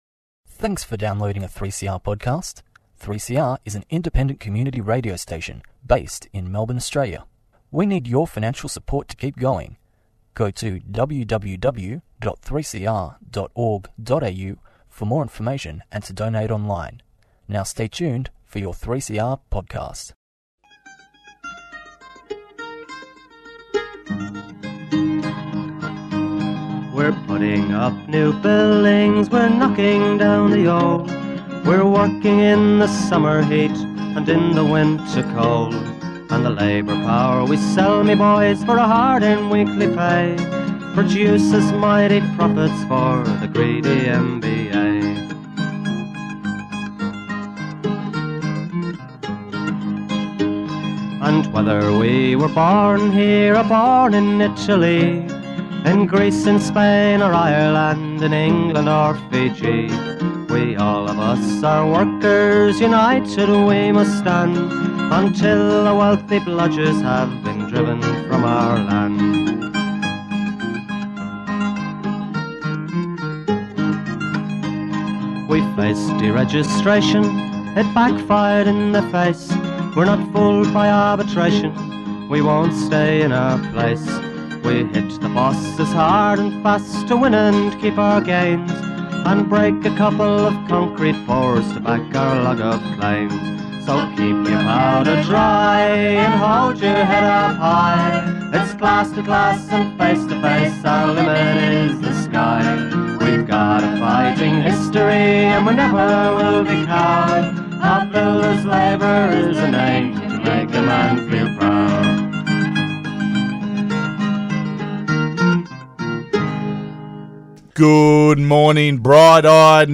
Tweet Concrete Gang Sunday 9:30am to 10:00am Local and national building issues presented by the CFMEU Building Union Division.